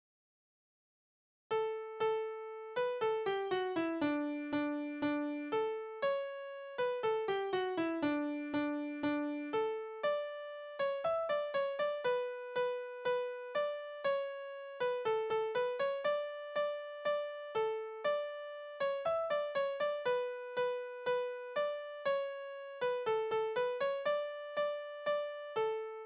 Tonart: D-Dur
Taktart: 4/4
Tonumfang: große None
Besetzung: vokal